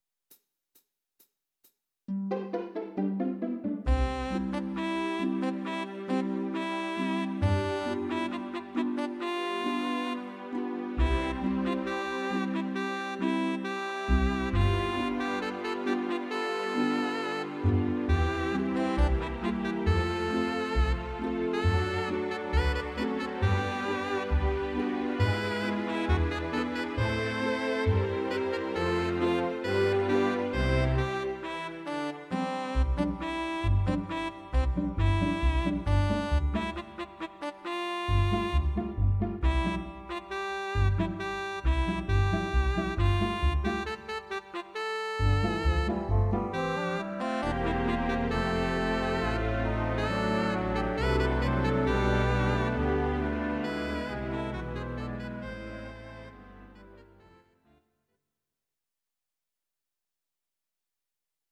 Audio Recordings based on Midi-files
Musical/Film/TV, Dutch